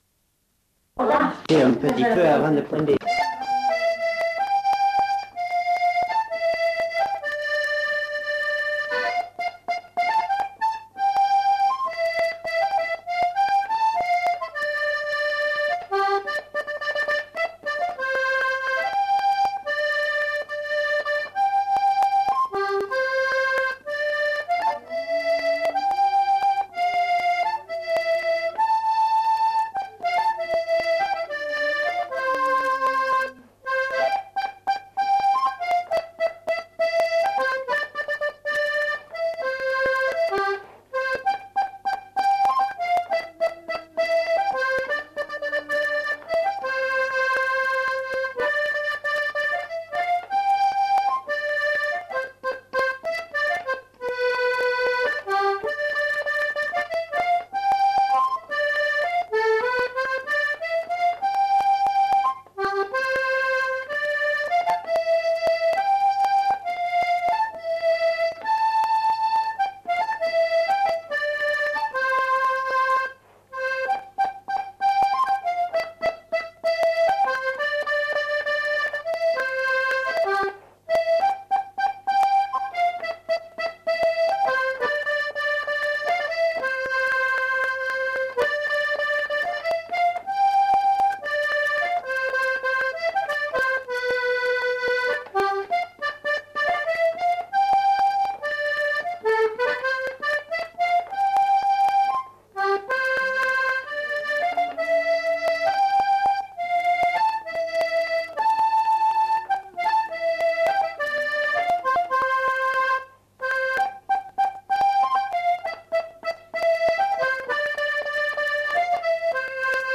Aire culturelle : Agenais
Lieu : Galapian
Genre : morceau instrumental
Instrument de musique : accordéon diatonique
Danse : valse